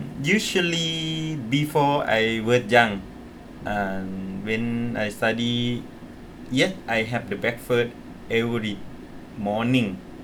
S1 = Brunei female S2 = Laos male
Intended Word: young Heard as: junk Discussion: The initial consonant is [dʒ] rather than [j].